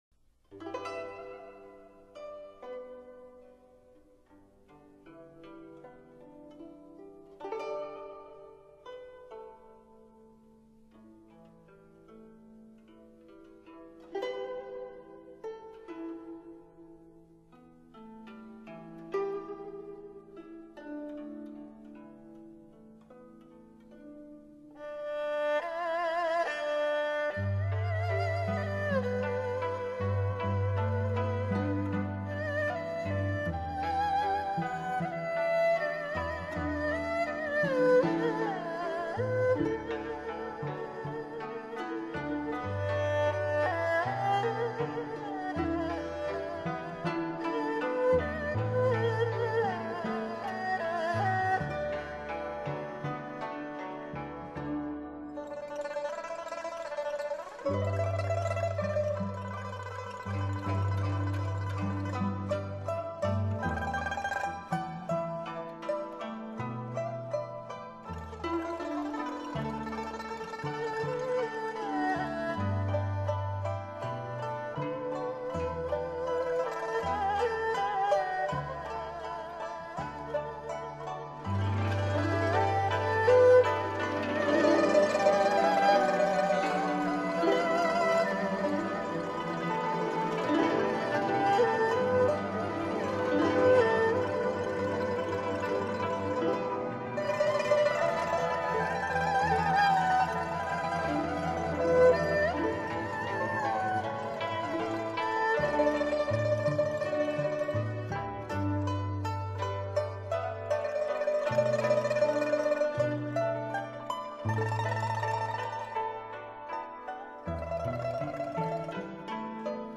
二胡
古筝
扬琴
琵琶